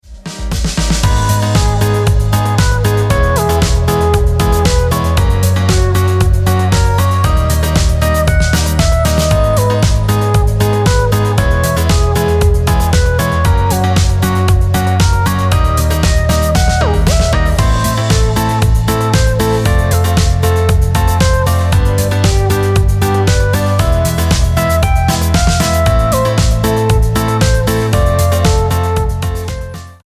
Begleitmusik zum Training auf dem Mini-Trampolin